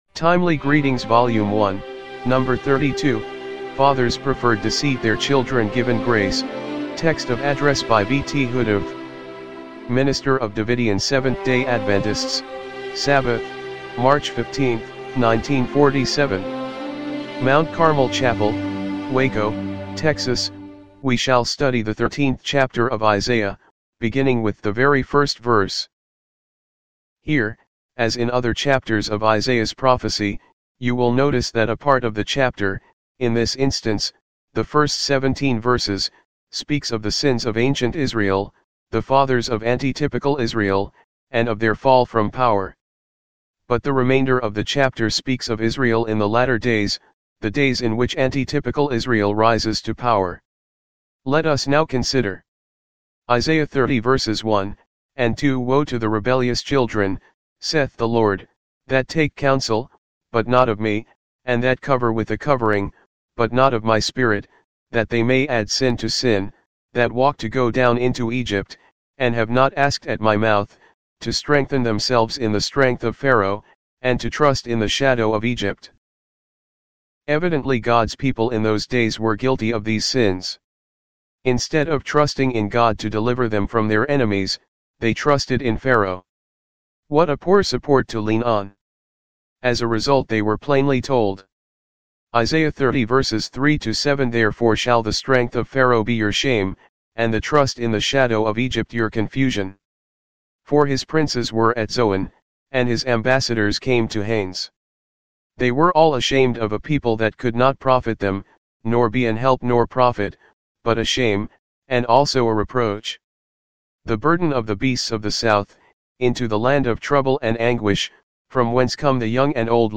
timely-greetings-volume-1-no.-32-mono-mp3.mp3